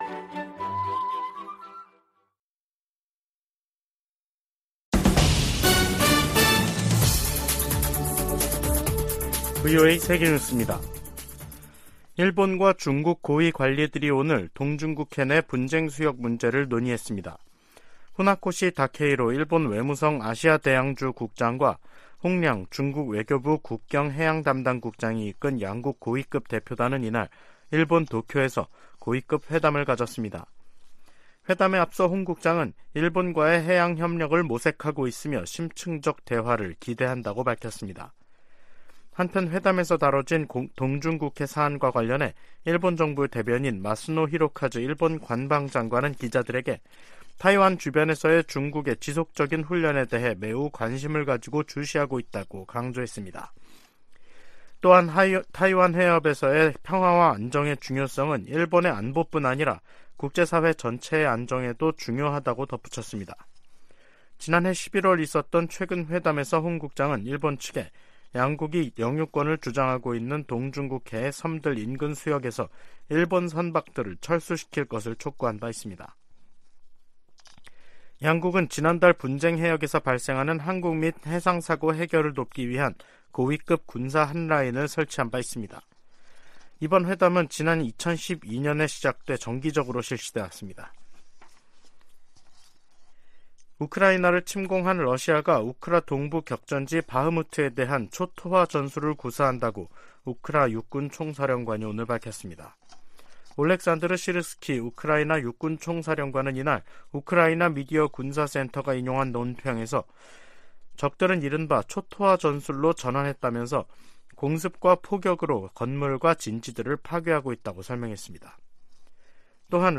VOA 한국어 간판 뉴스 프로그램 '뉴스 투데이', 2023년 4월 10일 2부 방송입니다. 한국 대통령실은 미국 정보기관의 국가안보실 감청정황 보도에 관해 "필요할 경우 미국에 합당한 조치를 요청할 것"이라고 밝혔습니다. 미국 의원들이 윤석열 한국 대통령 의회 연설 초청을 환영한다고 밝혔습니다. 북한이 '수중핵어뢰'로 알려진 핵무인 수중 공격정 '해일'의 수중 폭파시험을 또 다시 진행했다고 밝혔습니다.